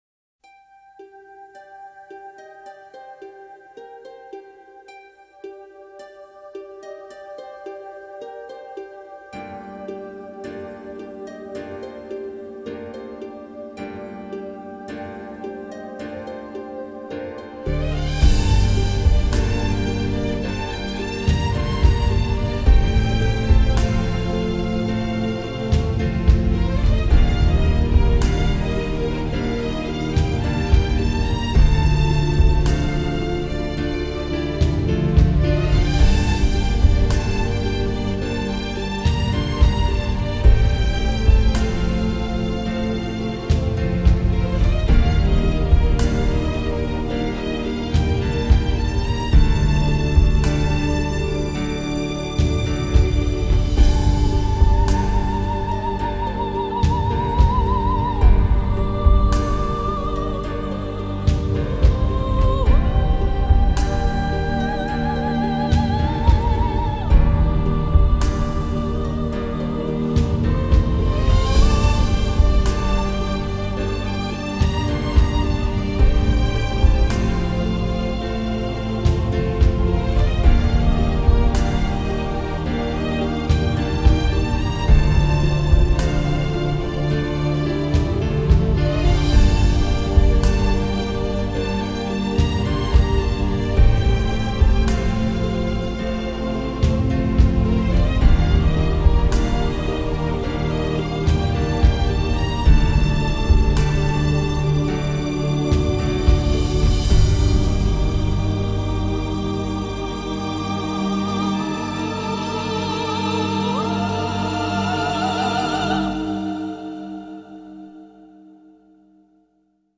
在近衛說故事的過程中這首BGM悄悄帶入，掀起了整集的劇情高潮。